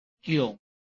臺灣客語拼音學習網-客語聽讀拼-海陸腔-鼻尾韻
拼音查詢：【海陸腔】giung ~請點選不同聲調拼音聽聽看!(例字漢字部分屬參考性質)